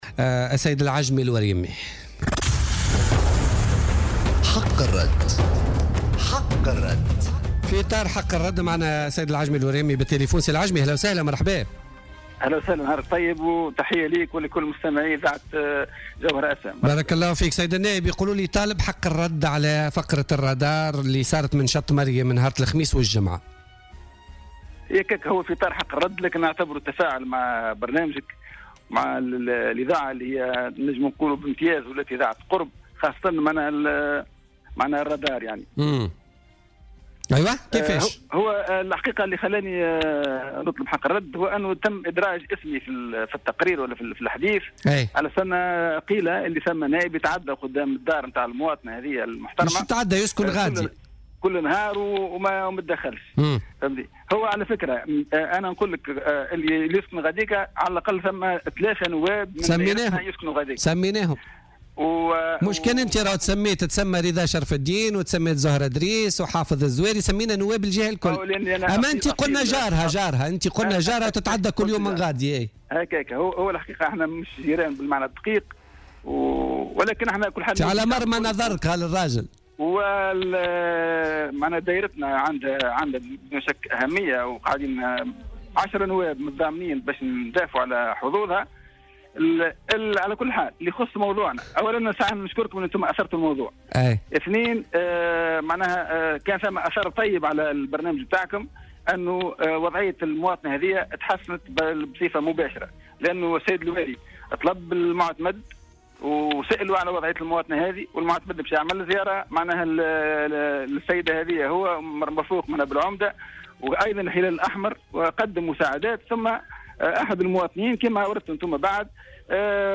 تدخّل اليوم النائب العجمي الوريمي من حركة النّهضة للردّ بعد التطرق لاسمه في تقرير مصور للرادار قام بتصوير وضع صعب لمواطنة تعيش في بيت دون سقف في منطقة شط مريم من ولاية سوسة.